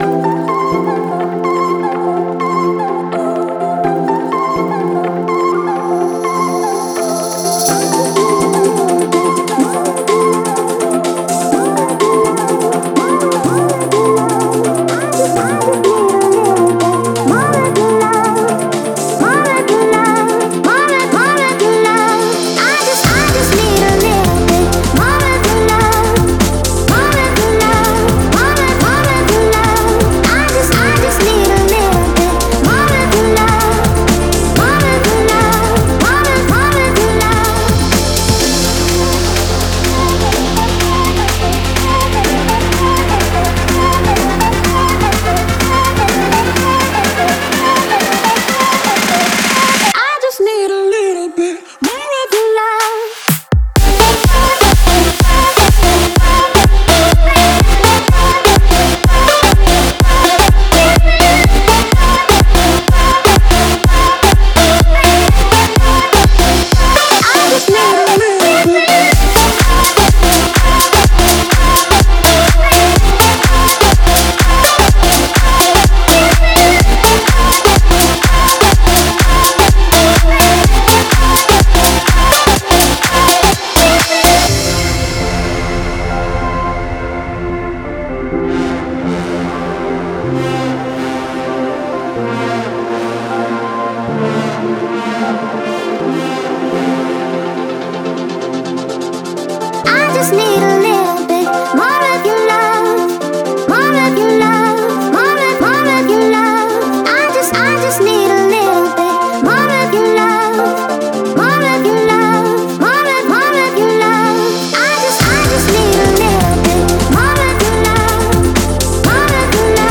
это энергичная трек в жанре электронной танцевальной музыки